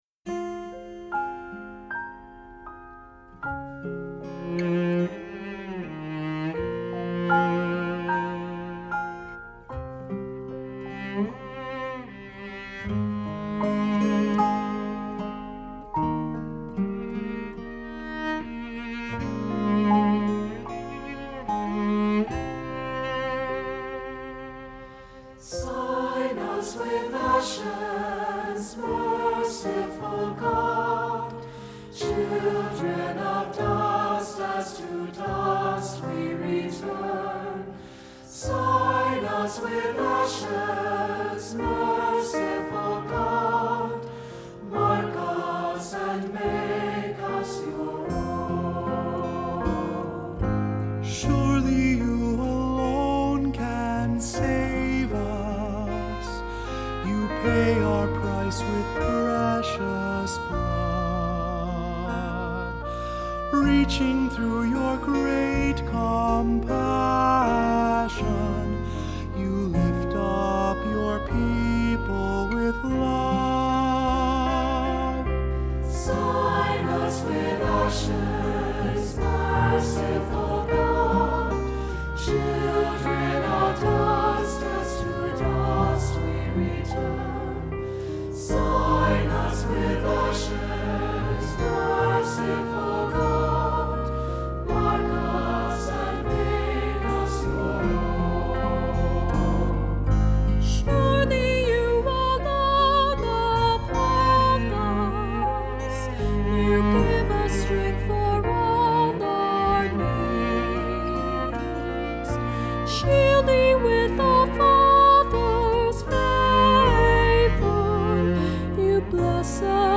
Voicing: SAB; Cantor; Solo; Assembly